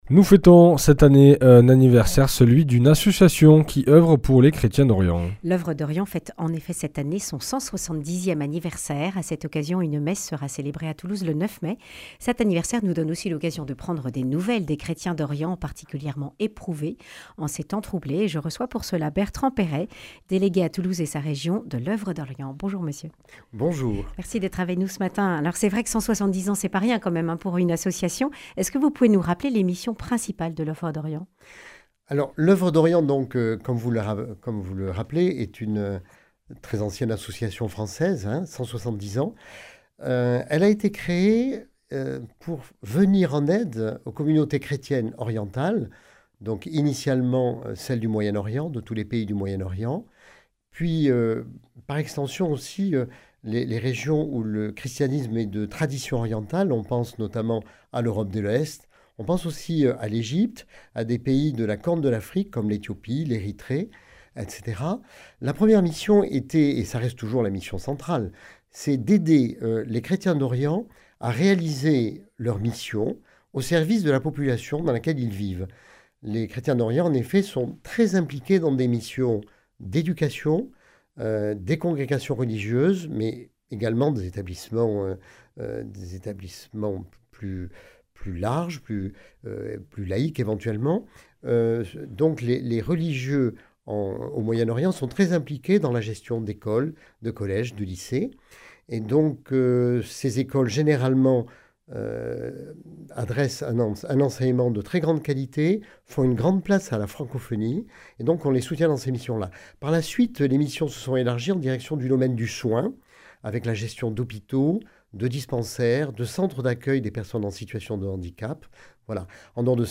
Accueil \ Emissions \ Information \ Régionale \ Le grand entretien \ L’Oeuvre d’Orient a 170 ans !